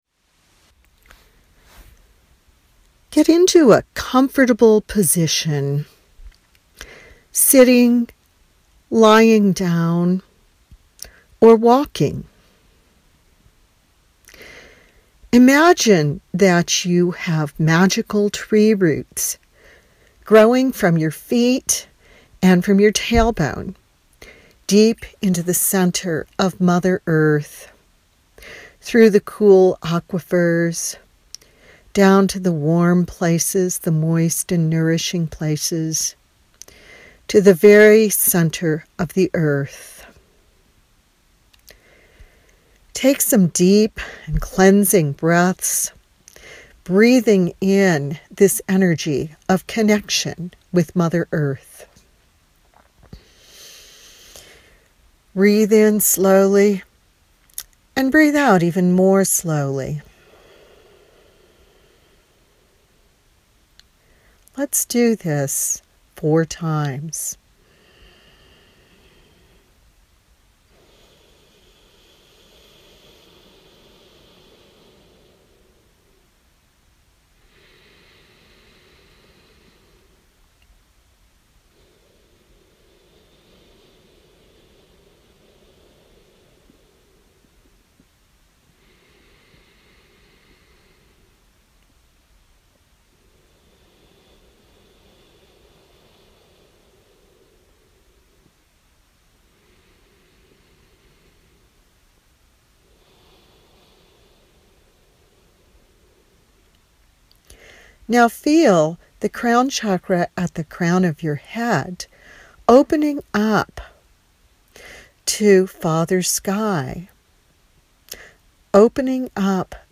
Listen to your FREE Climate Healing Meditation
climate-healing-meditation_converted.mp3